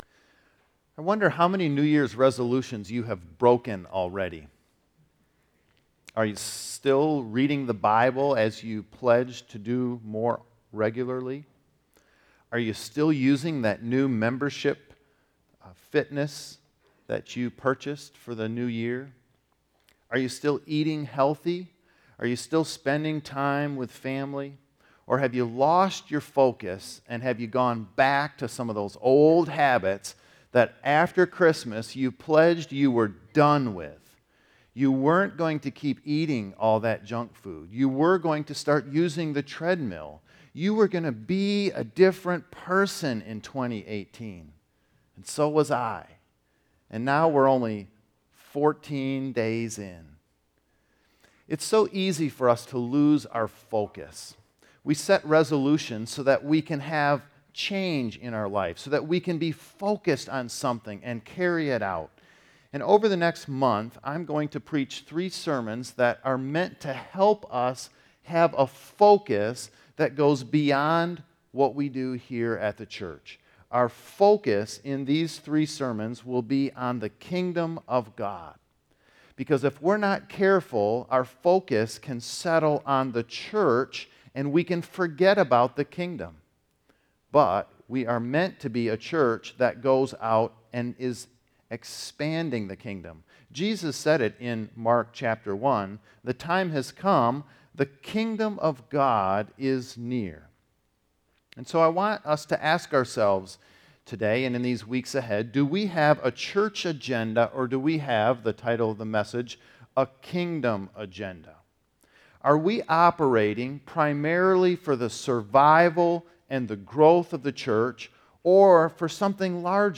Sermons | Woodhaven Reformed Church